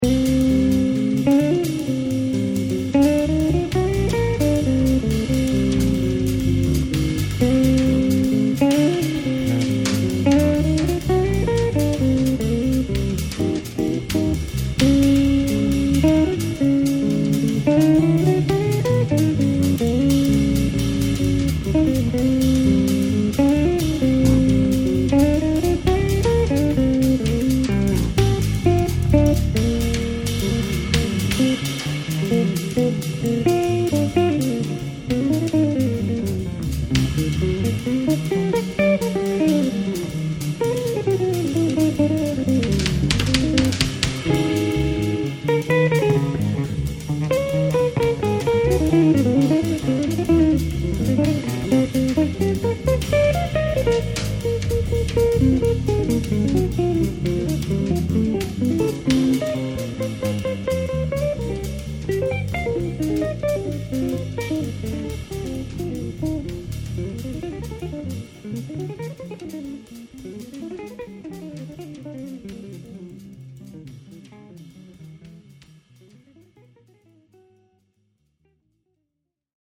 Fast Bop